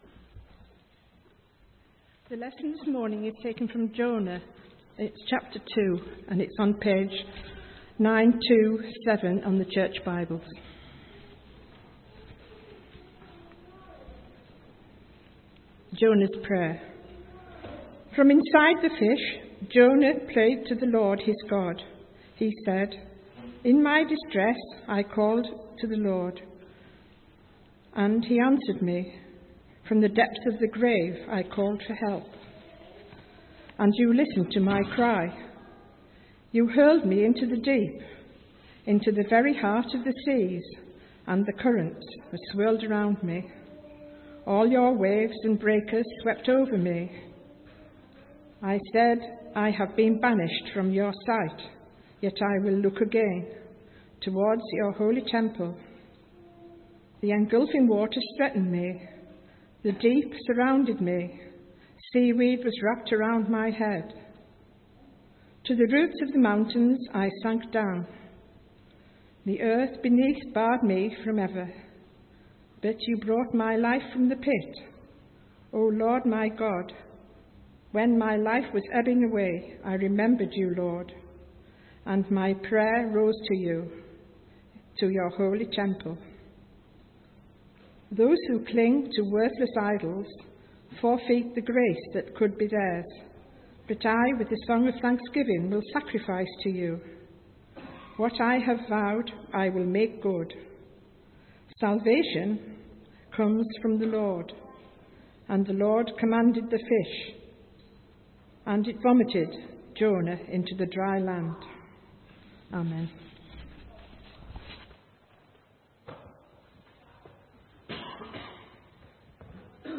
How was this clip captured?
From Service: "10.45am Service"